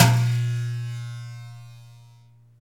PRC RATTL02L.wav